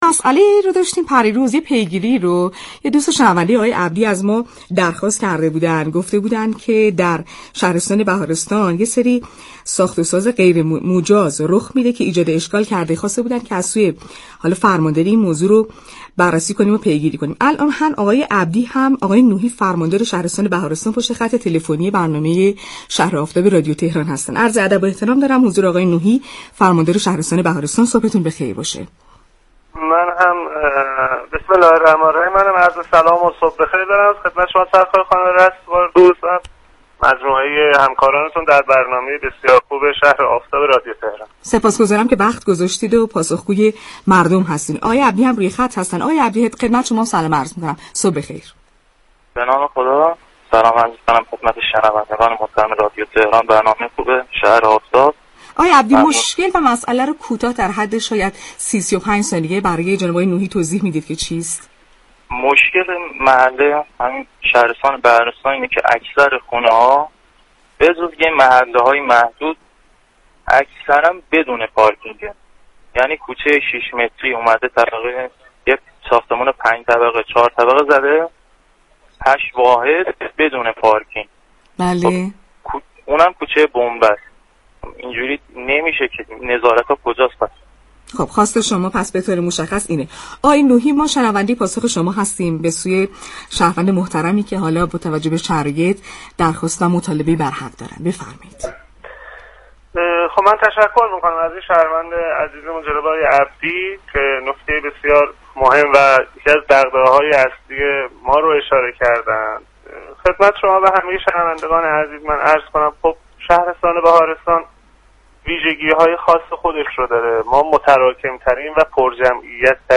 به گزارش پایگاه اطلاع رسانی رادیو تهران، یكی از بخش‌های برنامه «شهر آفتاب» رادیو تهران ‌پیگیری مشكلات مخاطبان و دریافت پاسخ آنها از سوی مسئولان كشور است.